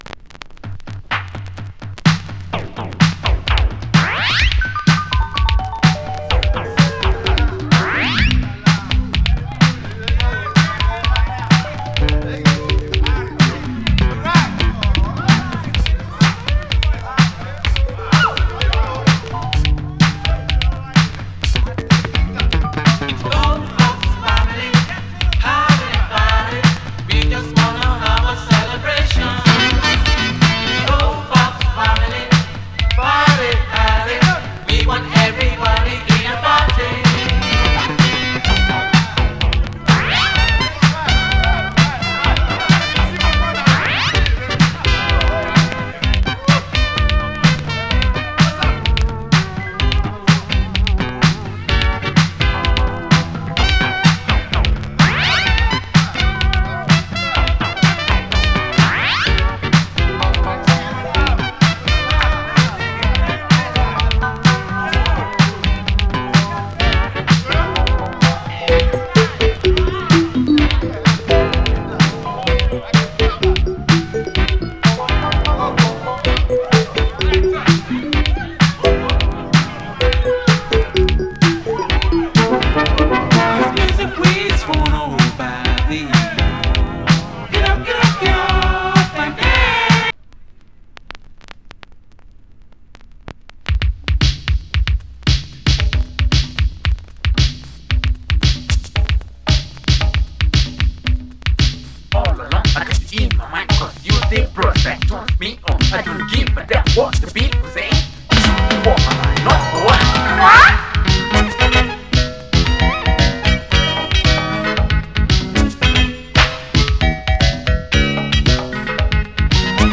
Genre: Electronic, Funk / Soul Style: Boogie, Funk